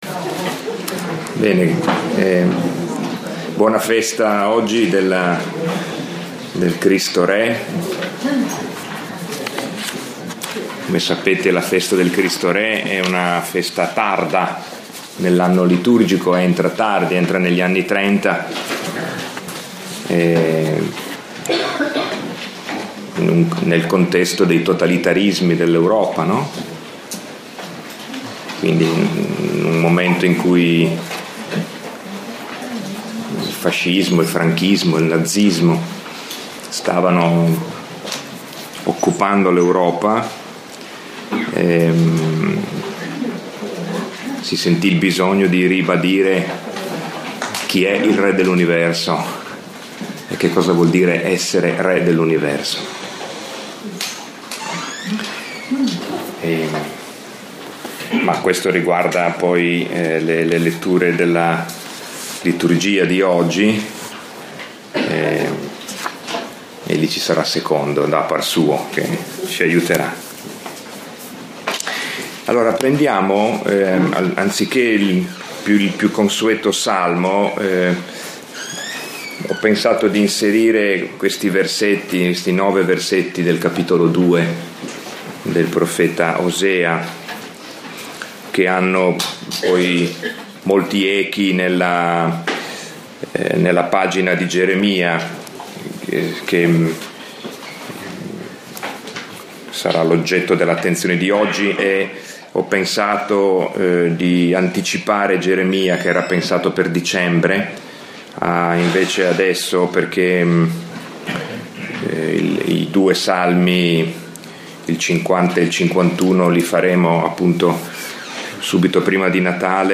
Lectio 2 – 20 novembre 2016